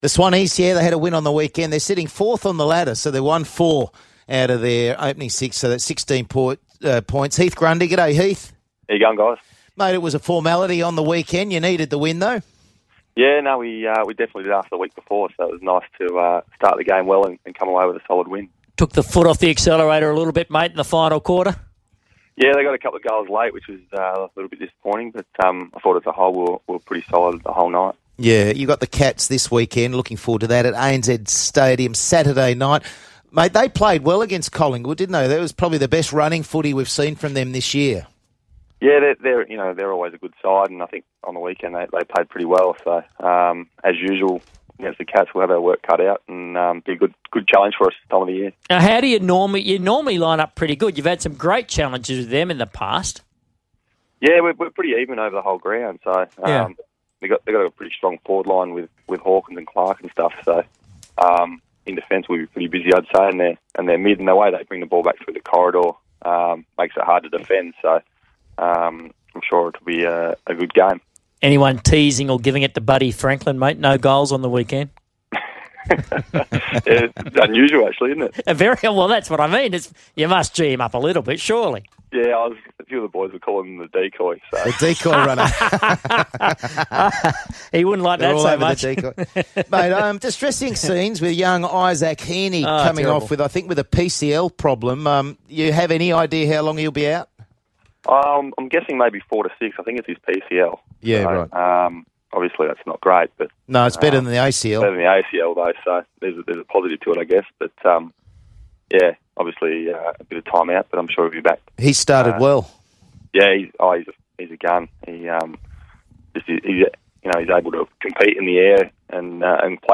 Swans defender Heath Grundy speaks to Big Sports Breakfast on Tuesday morning.